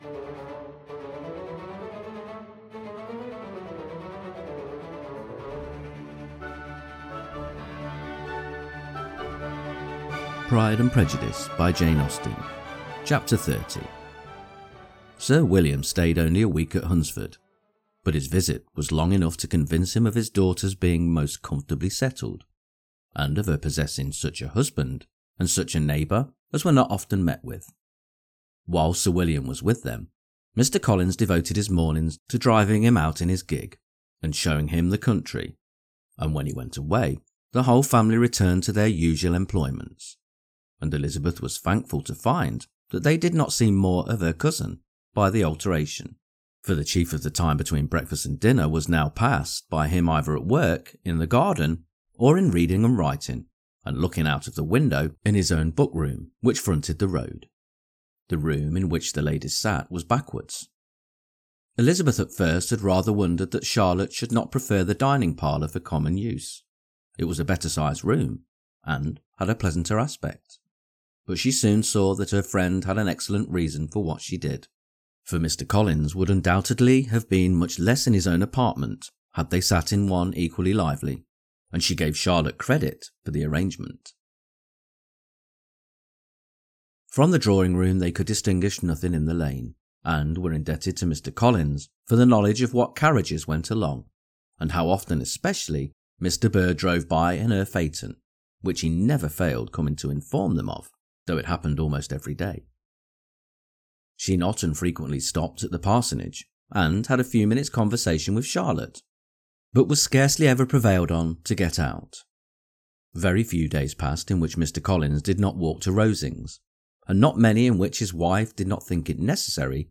Pride and Prejudice – Jane Austen Chapter 30 Narrated